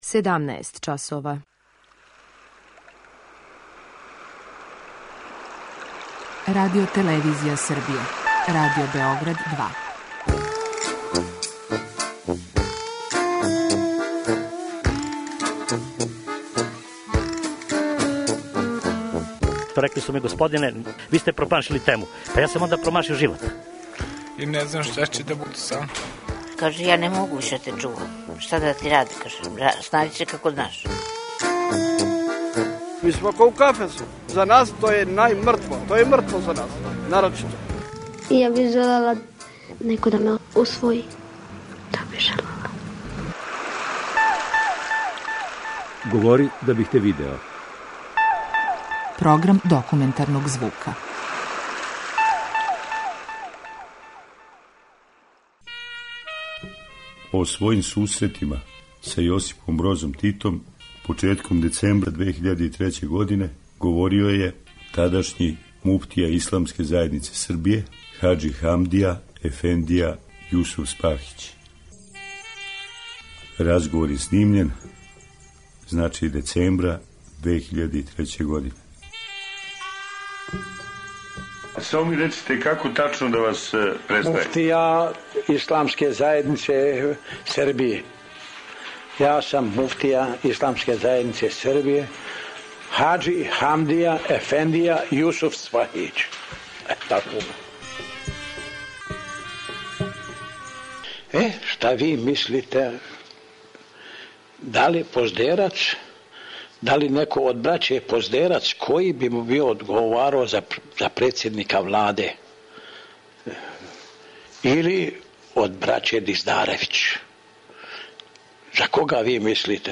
Dokumentarni program
- to je samo neposredni povod...O Josipu Brozu Titu i susretima s njim, govorio je 2003. godine tadašnji muftija Islamske zajednice Srbije hadži Hamdija efendija Jusufspahić.